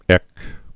(ĕk)